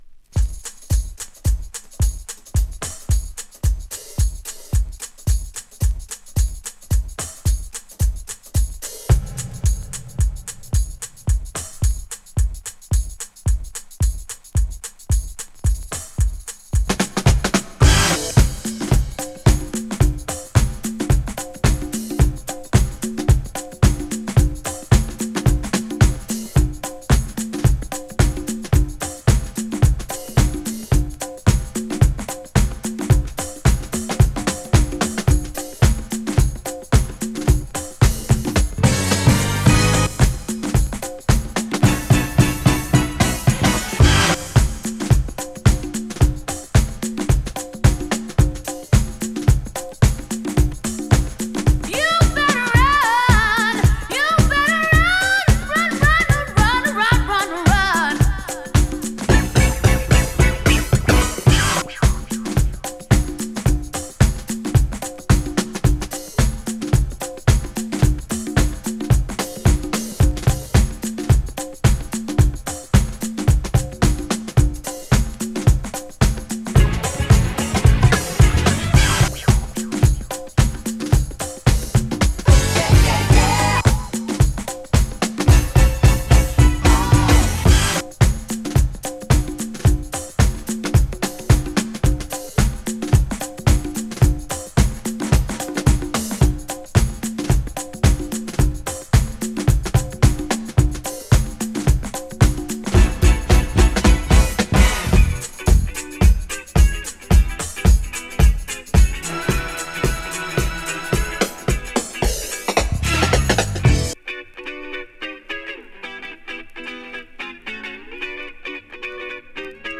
ゆったりとした